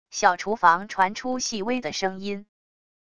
小厨房传出细微的声音wav音频